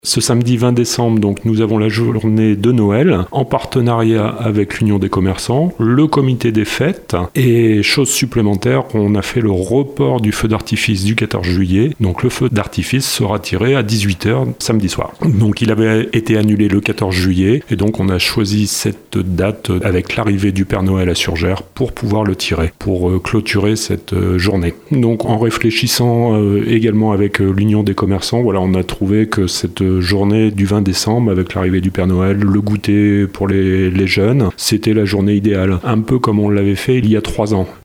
Un évènement très attendu par les petits et les grands, qui sera ponctué par la venue du Père Noël et le tir du feu d’artifice, qui avait dû être reporté cet été en raison de la canicule. Stéphane Augé, adjoint au maire en charge des animations :